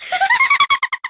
Candy Kong giggling
Candy's_giggle.oga.mp3